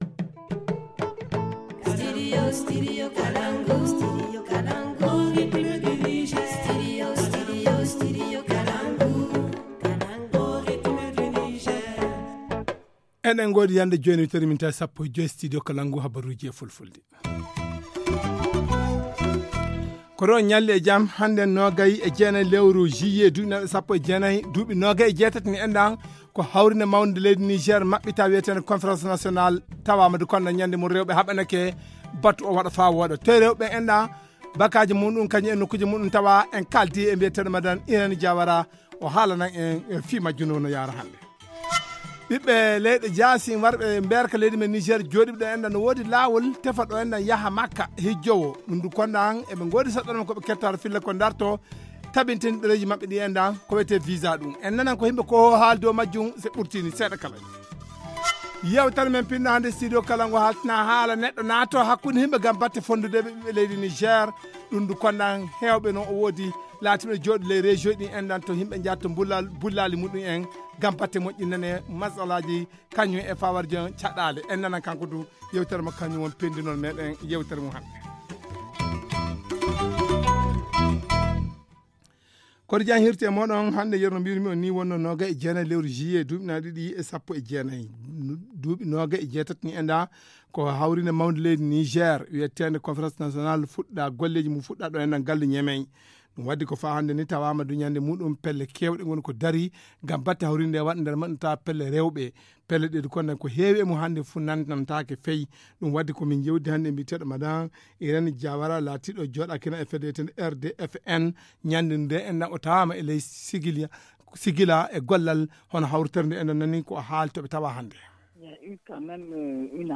Le journal en français